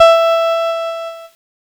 Cheese Note 10-E3.wav